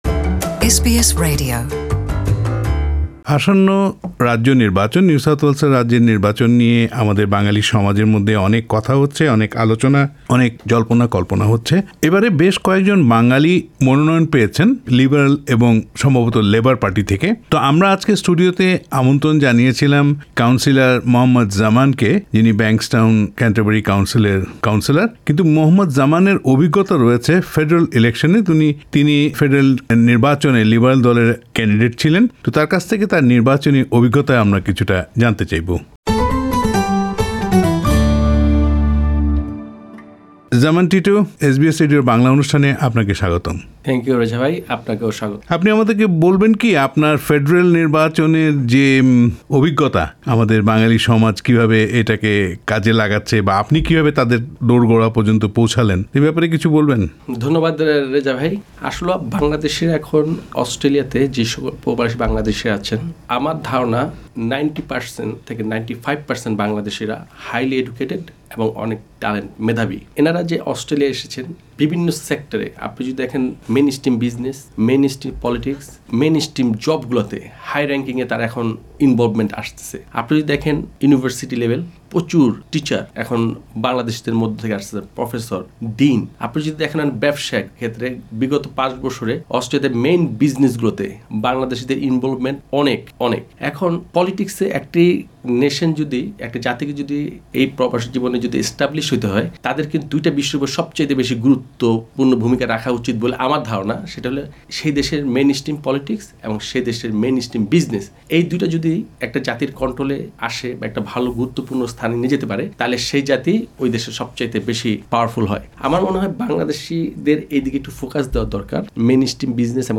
Interview with Shahe Zaman Titu Liberal Party councillor of Canterbury-Bankstown council